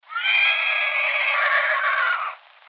horse.mp3